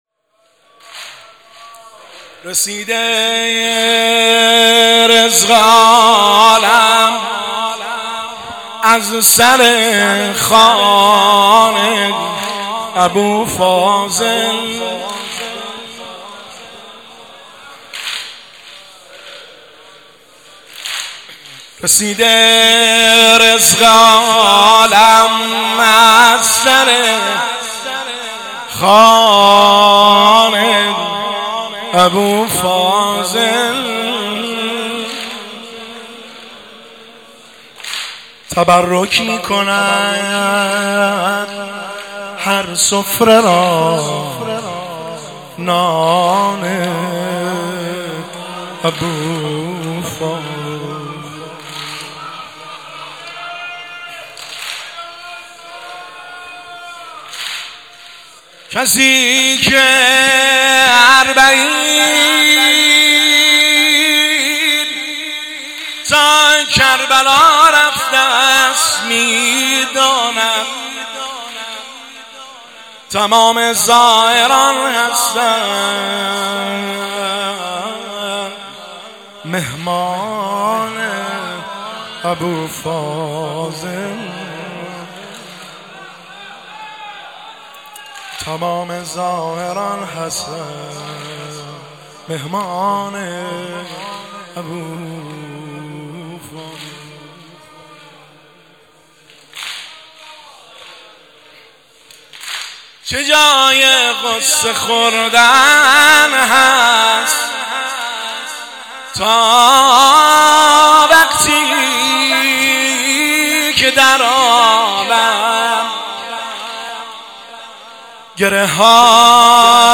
فاطمیه دوم 1403